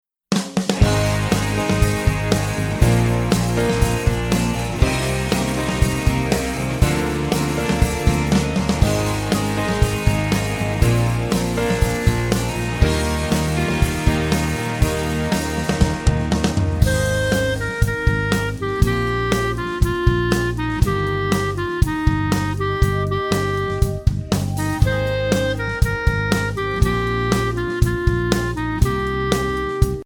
Voicing: Bb Clarinet